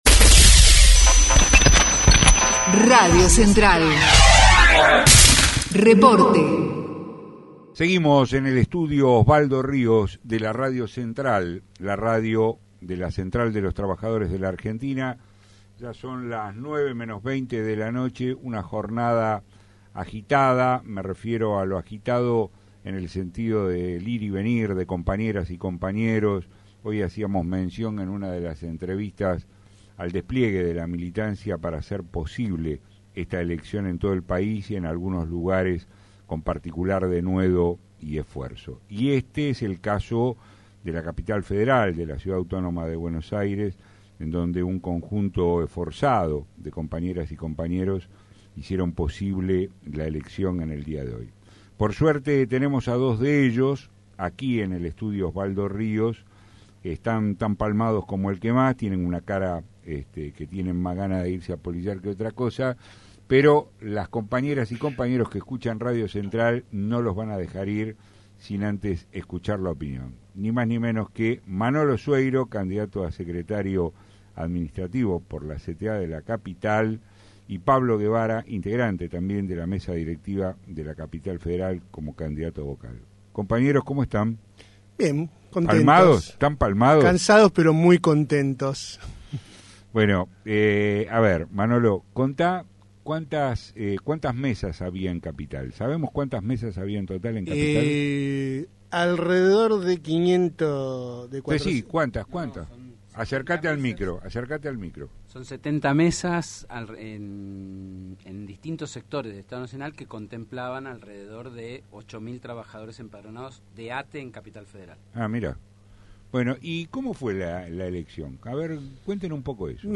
entrevista) RADIO CENTRAL